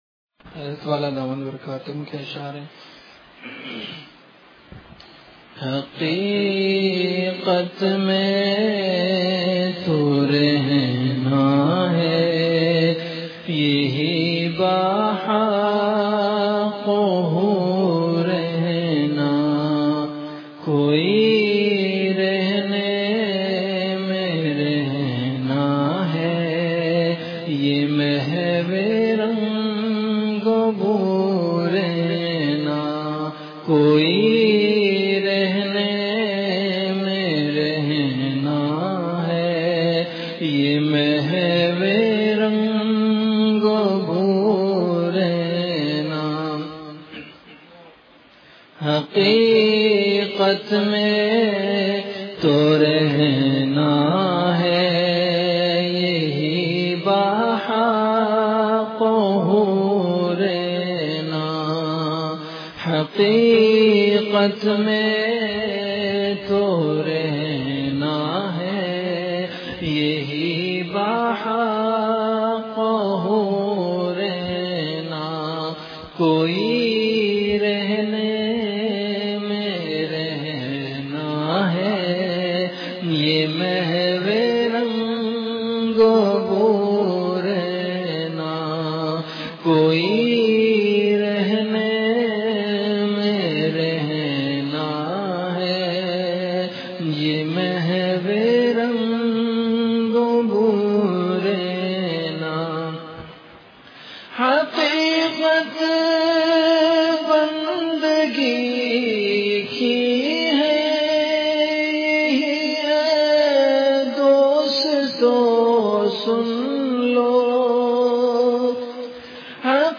Delivered at Khanqah Imdadia Ashrafia.
Ashaar · Khanqah Imdadia Ashrafia